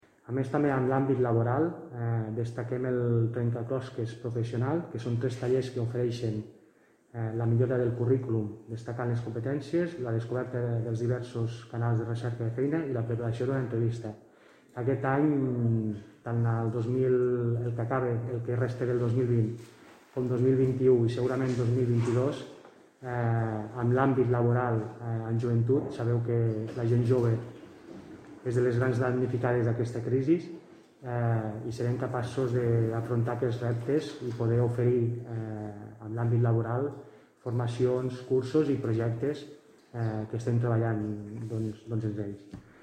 Fitxers relacionats AGENDA JOVE OCTUBRE-DESEMBRE 2020 (3.2 MB) Cartell AGENDA JOVE OCTUBRE-DESEMBRE 2020 (3.4 MB) Tall de veu del regidor de Joventut i Festes, Ignasi Amor, sobre la nova Agenda Jove (669.4 KB)
tall-de-veu-del-regidor-de-joventut-i-festes-ignasi-amor-sobre-la-nova-agenda-jove